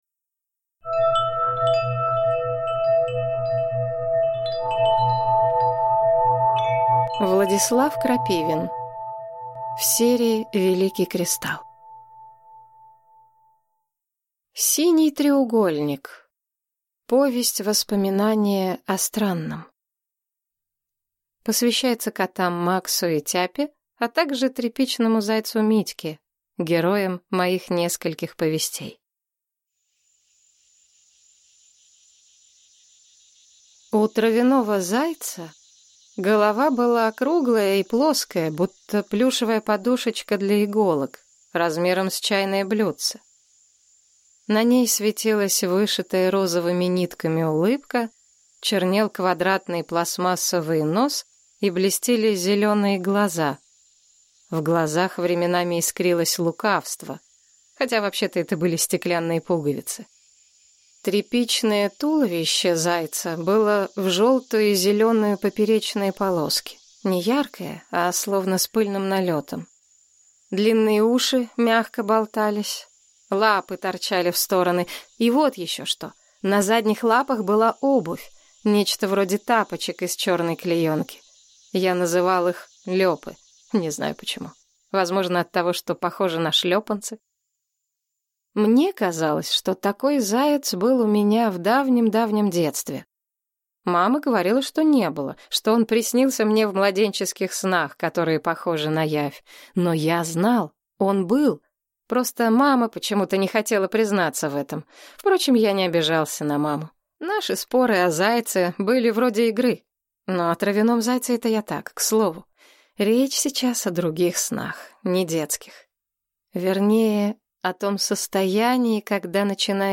Аудиокнига Синий треугольник | Библиотека аудиокниг